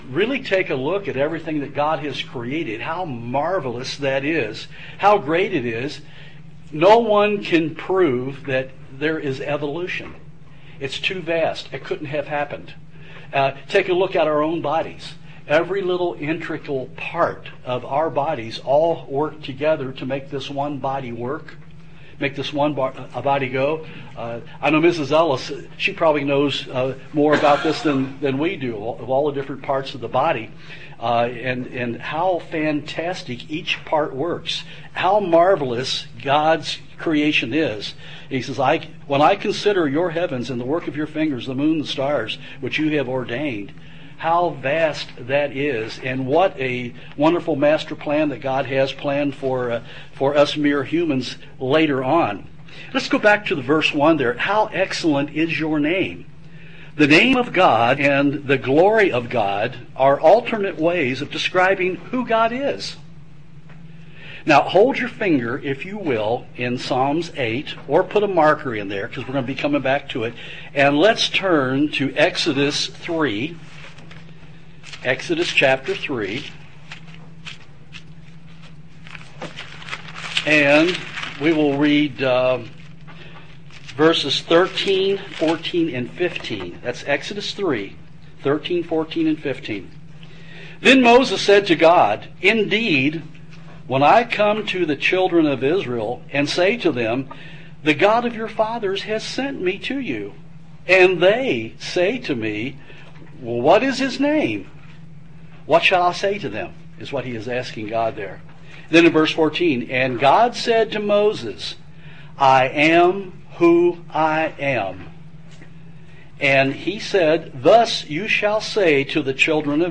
This sermon strives to show how great God is and why we should be in awe of His glory.